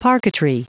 Prononciation du mot parquetry en anglais (fichier audio)
Prononciation du mot : parquetry